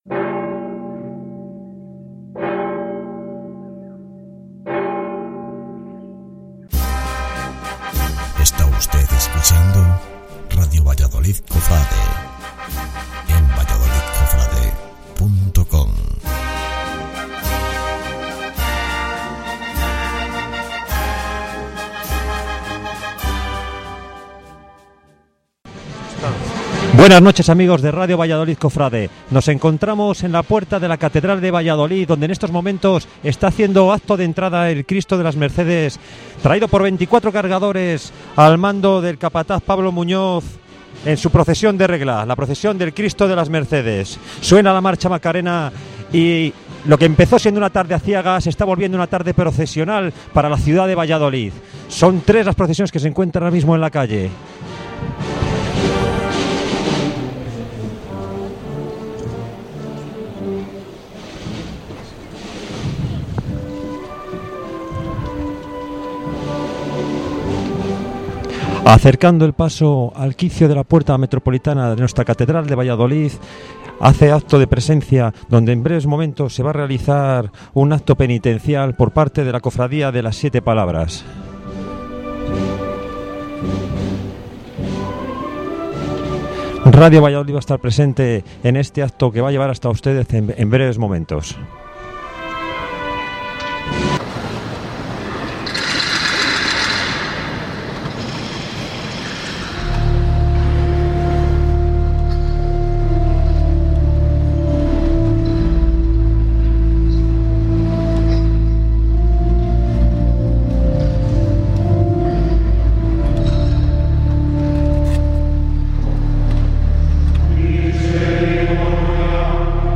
Procesión del Santo Cristo de las Mercedes y Miserere cantado en el Interior de la Catedral